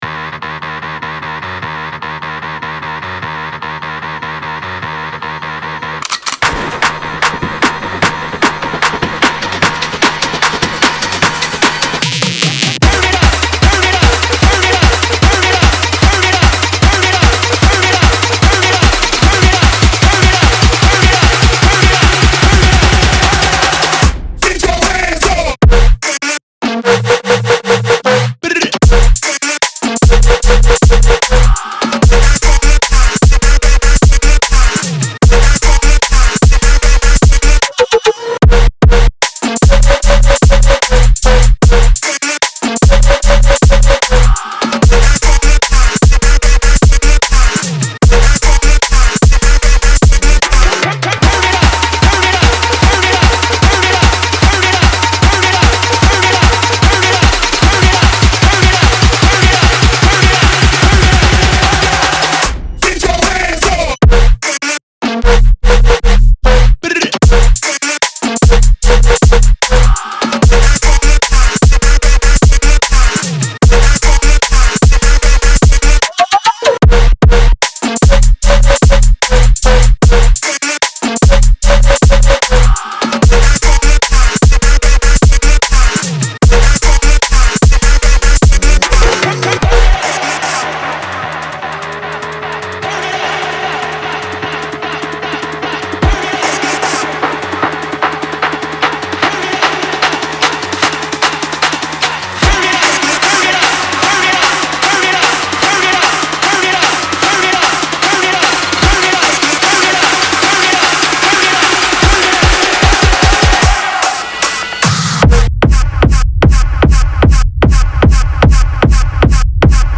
• Жанр: Dubstep